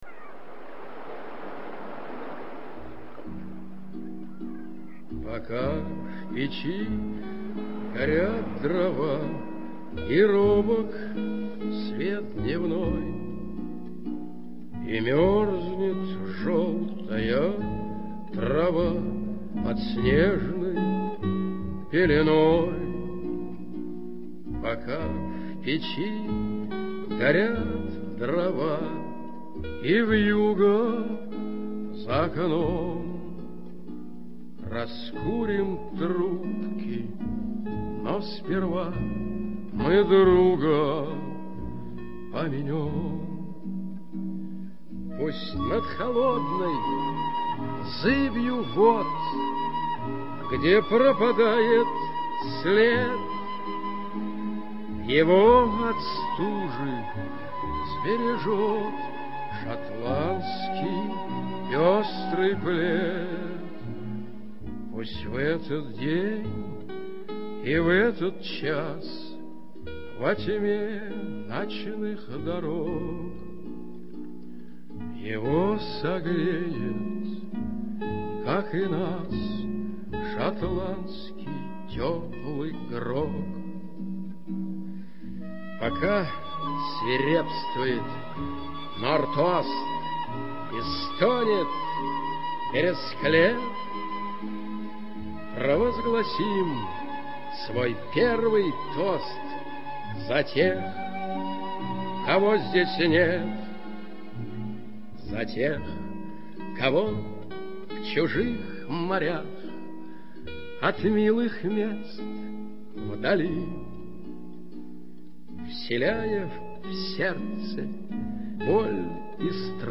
Исполняет: